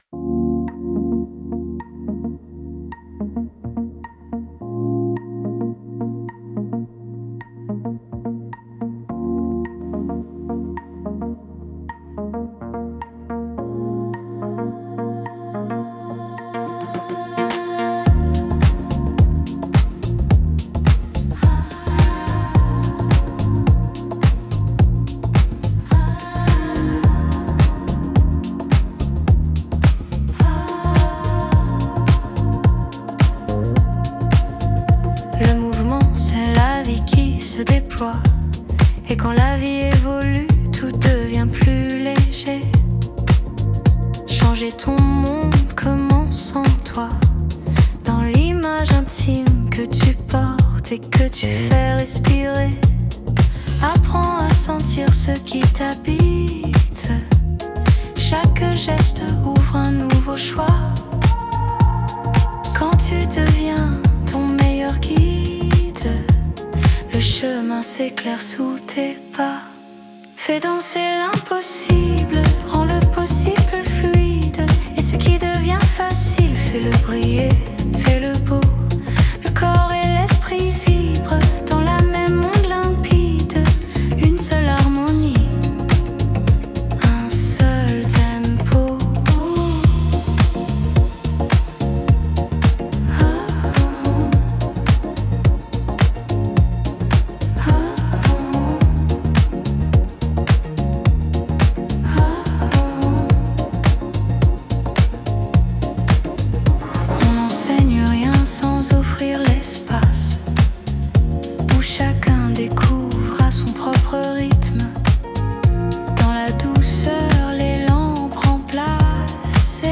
Les possibilités des systèmes d’IA permettent de passer en quelques clics d’une idée à un produit « fini ».